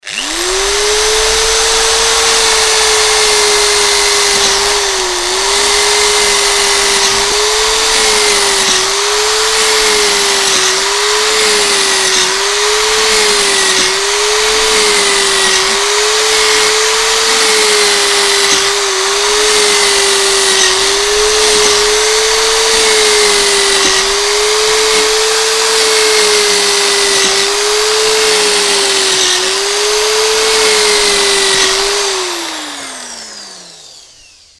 Все треки четкие и реалистичные.
звук дрели сверлящей стены